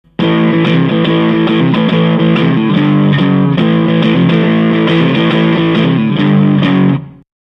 BM BOOSTER OFF(120kb,MP3)
Guitar Moon TeleType PU MIX
Amplifier VOX AD30VT AC15,GAIN10
録音はSM５７をあえて使用せず、「普通のマイク」です。
しかもPCのマイク端子に直挿しです。